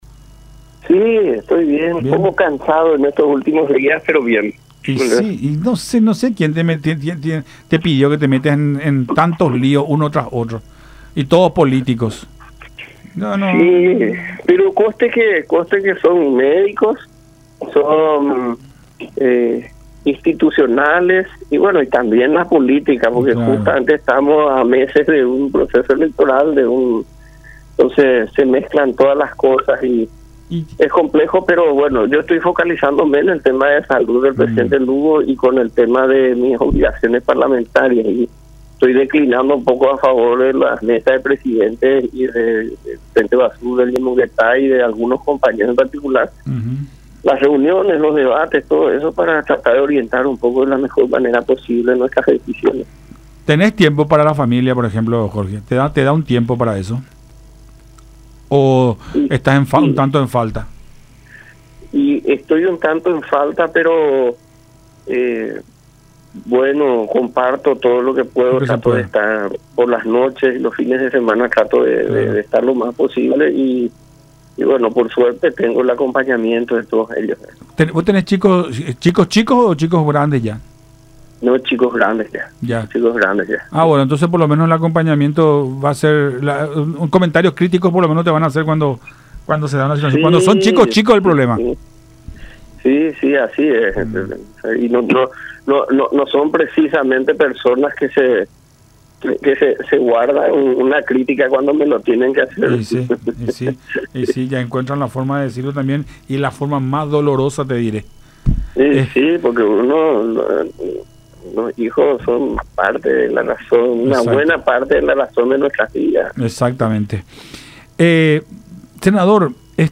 La Fiscalía estuvo floja en su actuación dentro de los marcos jurídicos”, dijo Querey en diálogo con Nuestra Mañana a través de Unión TV y radio La Unión.